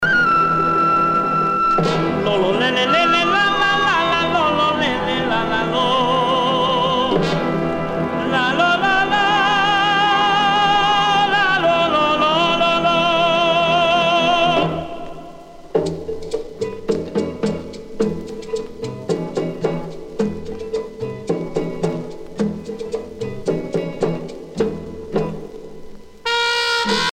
danse : rumba